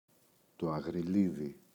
αγριλίδι,το [aγriꞋλiði]